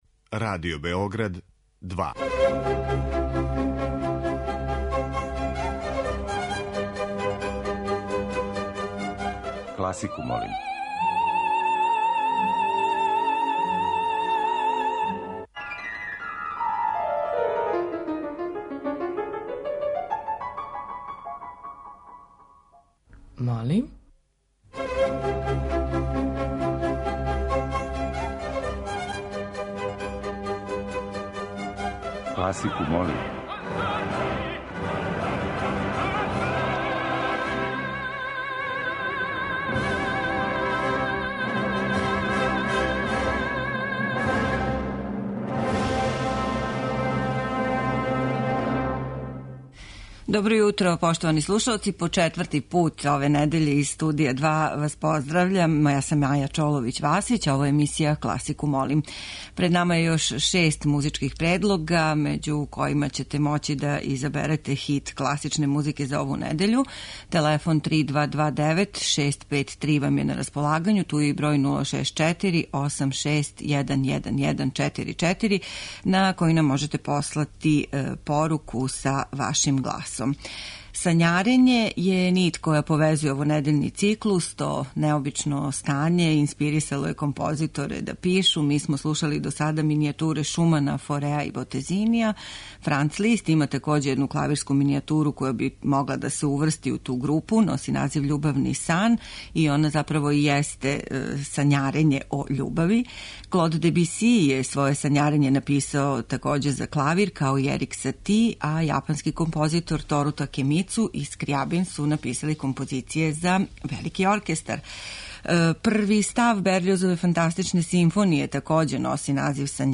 Овонедељни циклус обједињен је делима која су композитори написали инспирисани сањарењем. Уживо вођена емисија Класику, молим окренута је широком кругу љубитеља музике и разноврсног је садржаја.